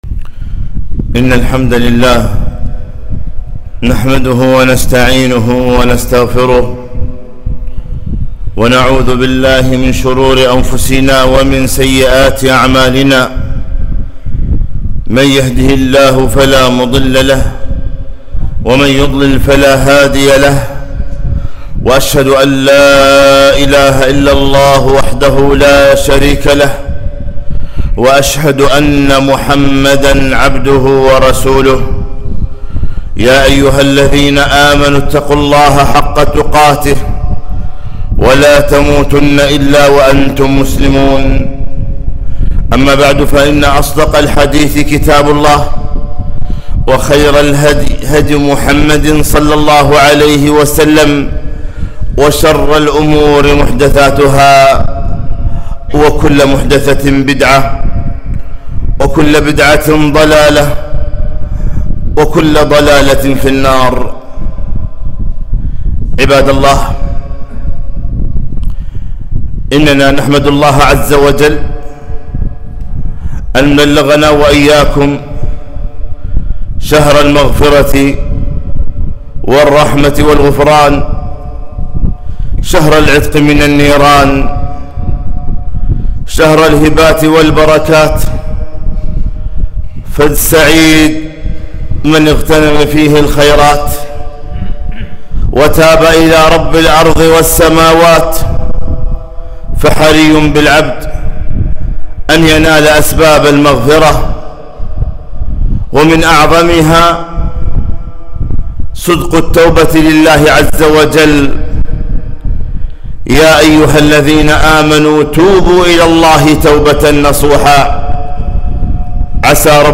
خطبة - ( توبوا إلى الله جميعا أيه المؤمنون لعلكم تفلحون)